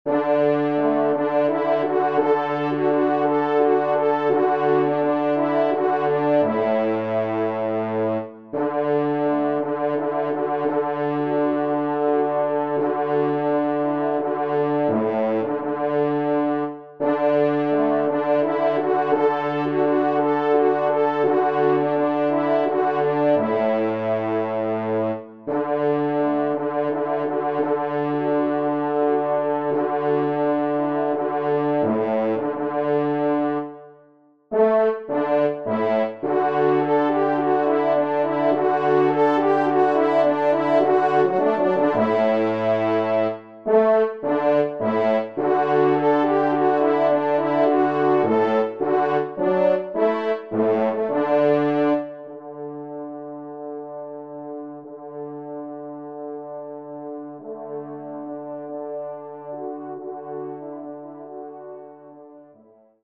4ème Trompe